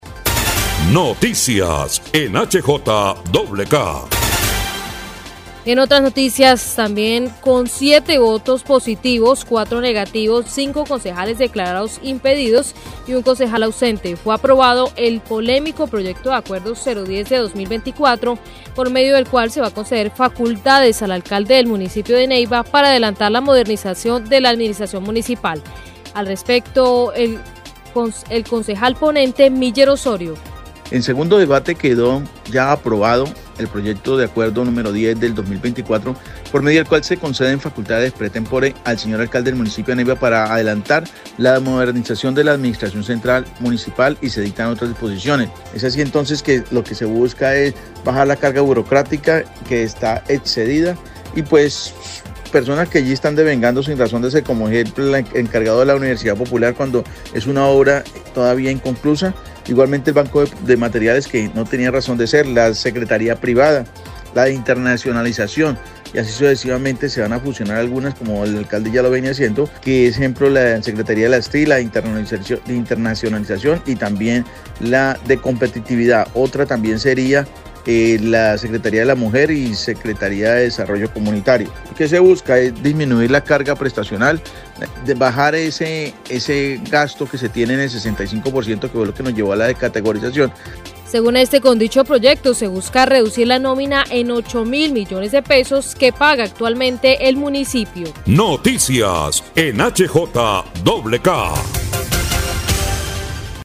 El concejal Miller Osorio, ponente del proyecto, expresó, “la tranquilidad es que de esta forma vamos a mejorar las finanzas y esos dineros que se estaban gastando injustificadamente se puedan destinar para pagar esa cantidad de obligaciones financieras que tenemos”.
NOTA_CONCEJAL_MILLER.mp3